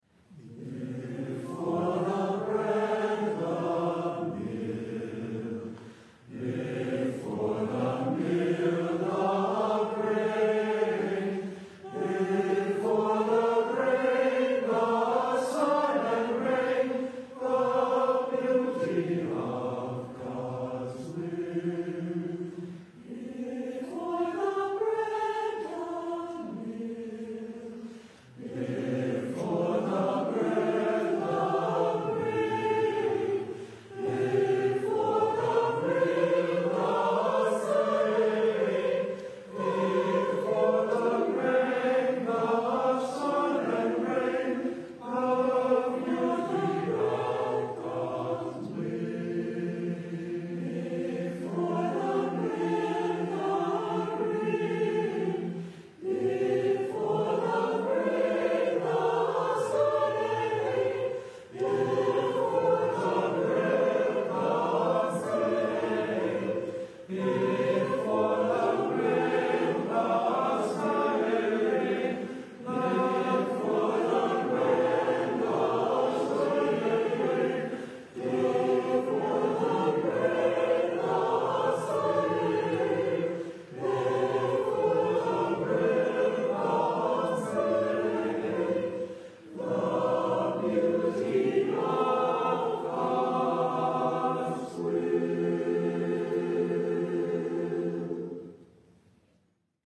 conductor
SATB a cappella